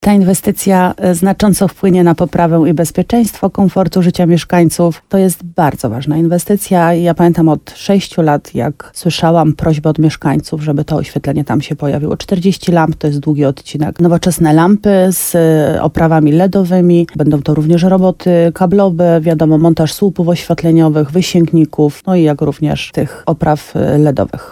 Jak mówiła w programie Słowo za Słowo w radiu RDN Nowy Sącz wójt gminy Łabowa Marta Słaby, lampy będą montowane na odcinku prawie 1,5 km.